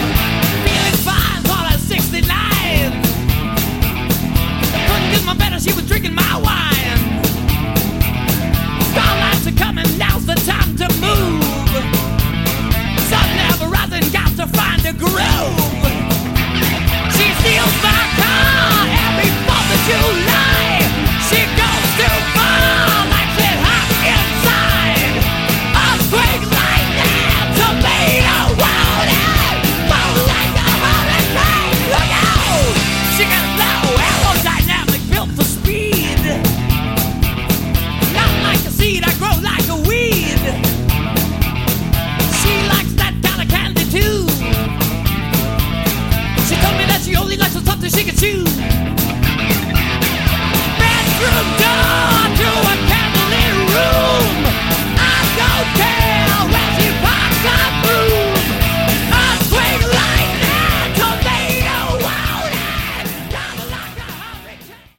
Category: Hard Rock
This album is pretty raw.
super-simplistic, mega-raw hard rock